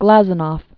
(glăzə-nôf, -nôv, glə-z-nôf), Aleksandr Konstantinovich 1865-1936.